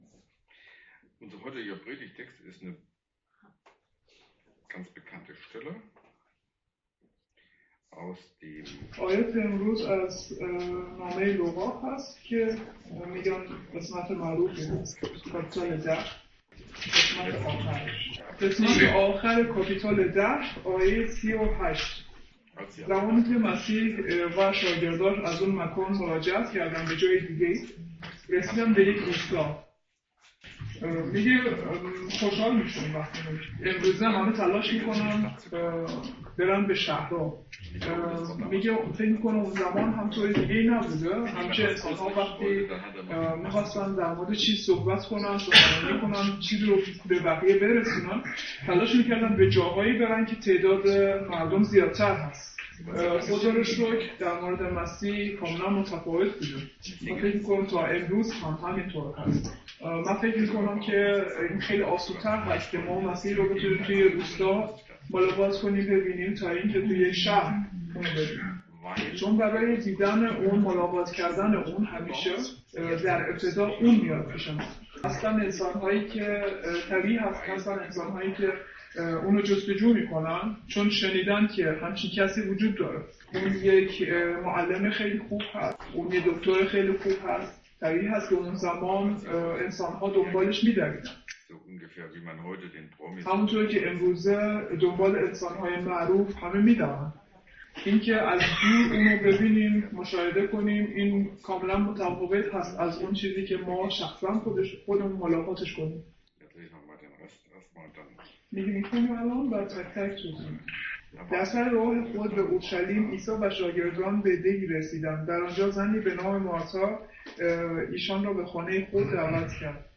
Übersetzung in Farsi